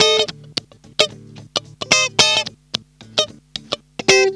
Funk Master Guitar 01.wav